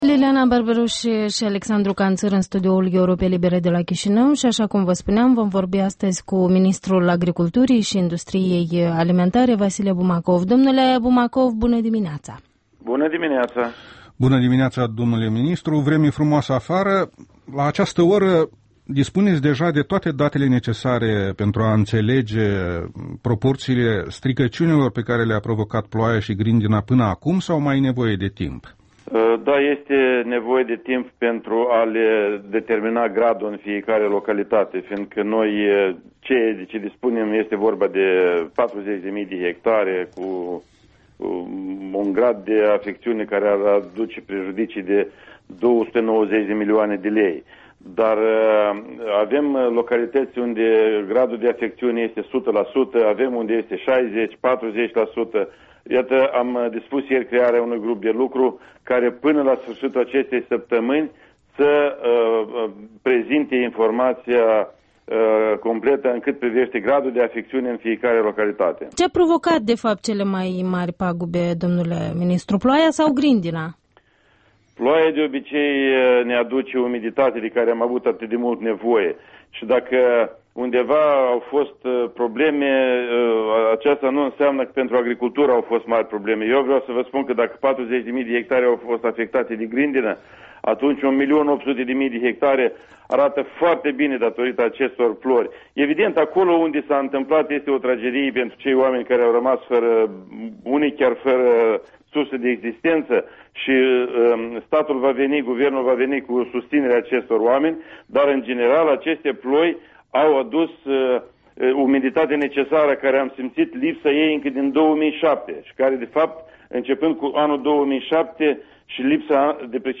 Interviul dimineții: cu Vasile Bumacov, ministrul Agriculturii şi Industriei Alimentare